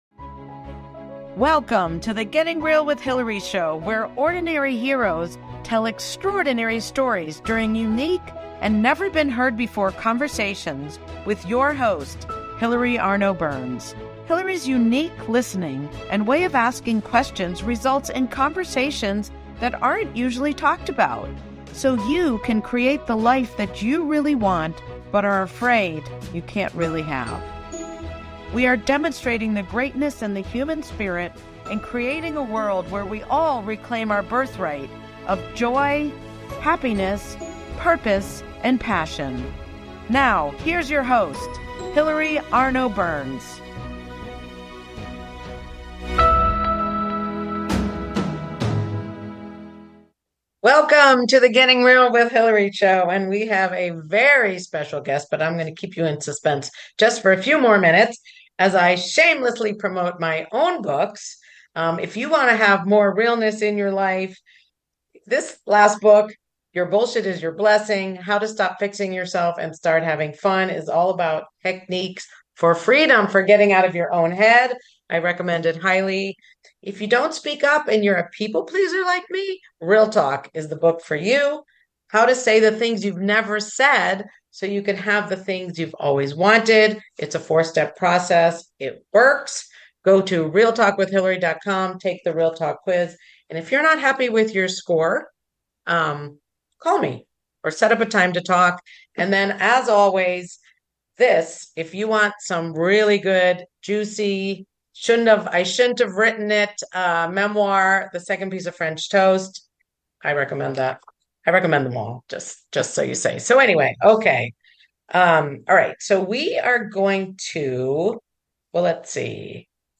Talk Show Episode, Audio Podcast
This week's interview had me nervous.